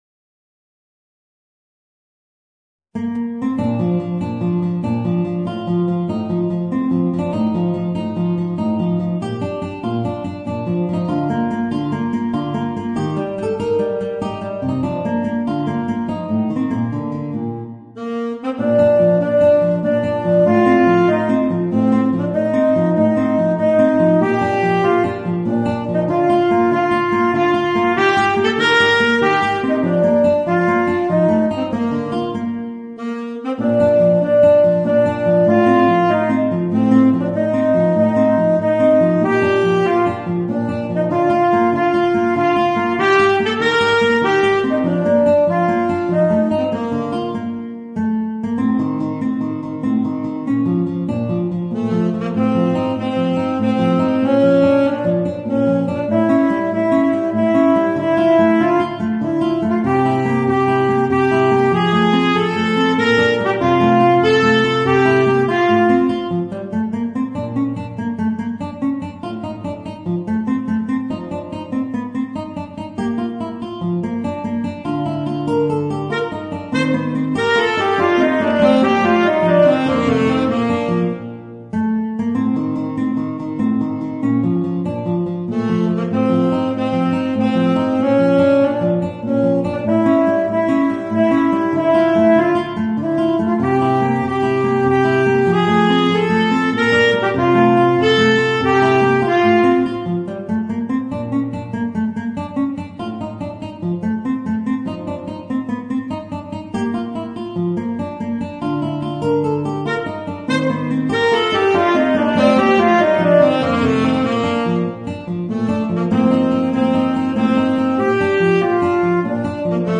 Voicing: Tenor Saxophone and Guitar